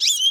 animalia_goldfinch_1.ogg